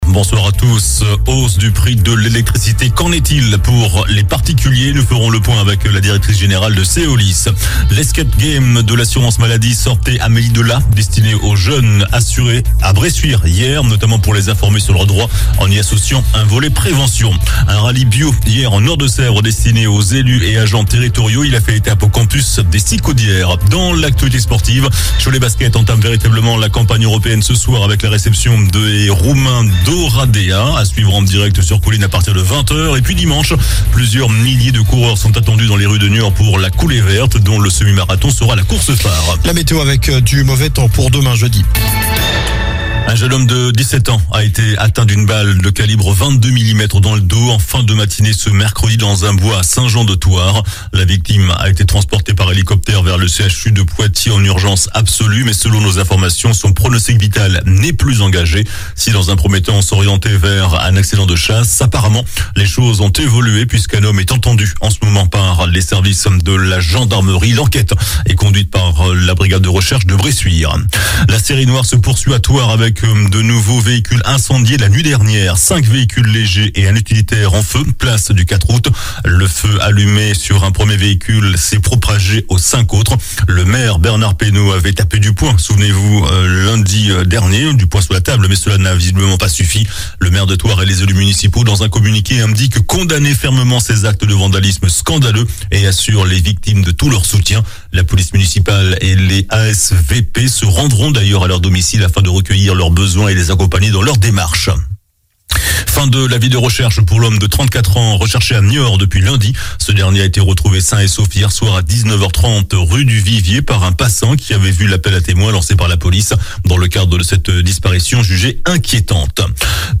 JOURNAL DU MERCREDI 12 OCTOBRE ( SOIR )